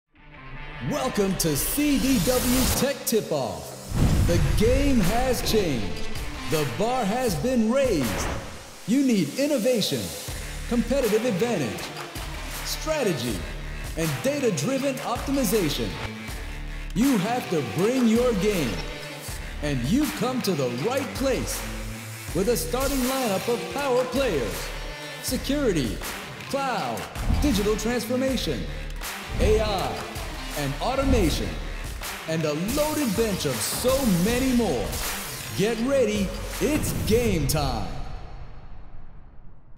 Professional American Male Voice Actor | Commercial, E-Learning & Corporate Narration
Commercial Demo
Known for a deep, authoritative voice as well as warm, conversational, and relatable reads, I provide versatile performances tailored to luxury brands, tech explainers, financial narration, medical content, network promos, political campaigns, and cinematic trailers.